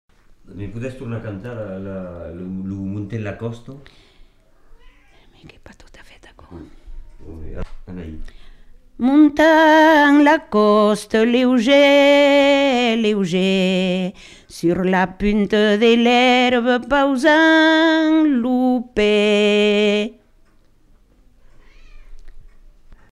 Aire culturelle : Marmandais gascon
Genre : chant
Effectif : 1
Type de voix : voix de femme
Production du son : chanté